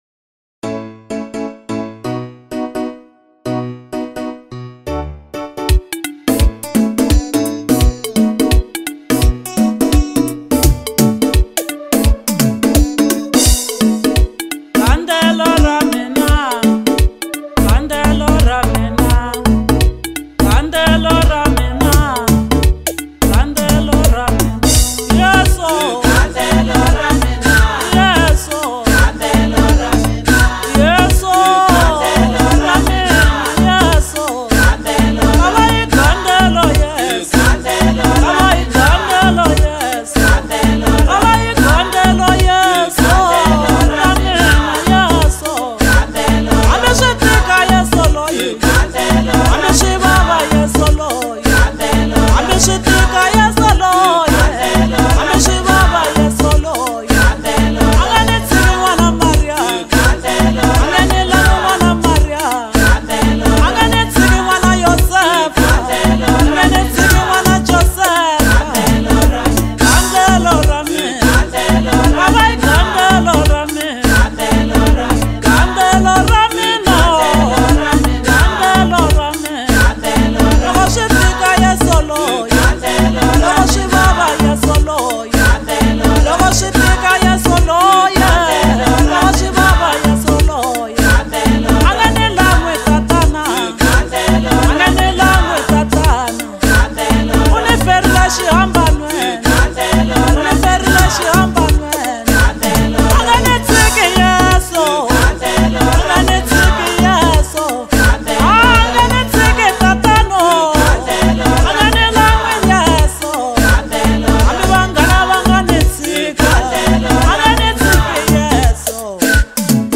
April 13, 2026 Maskandi Maskanda Maskandi Songs 0